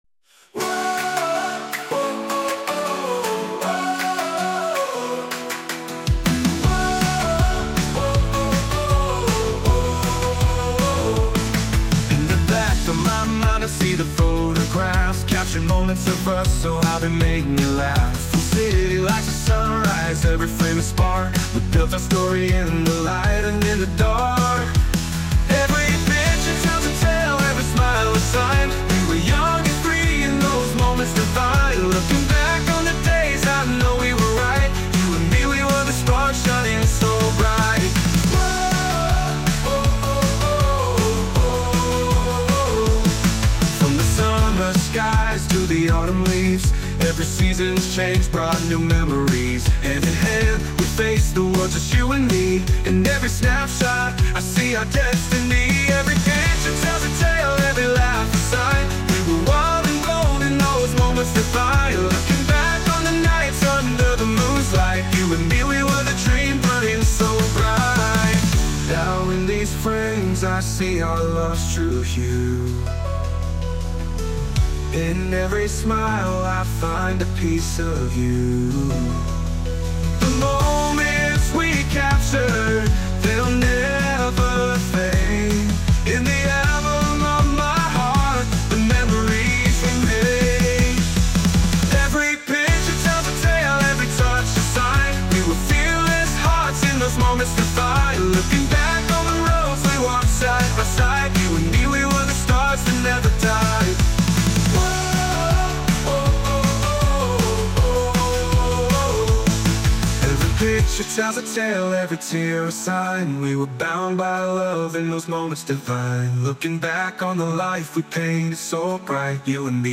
洋楽男性ボーカル著作権フリーBGM ボーカル
男性ボーカル洋楽 男性ボーカルオープニングムービー